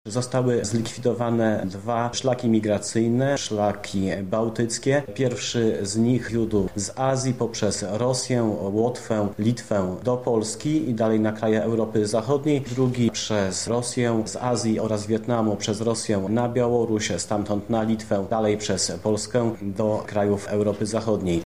z Nadbużańskiego Oddziału Straży Granicznej.